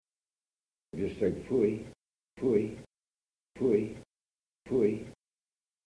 Pfui_Stout.mp3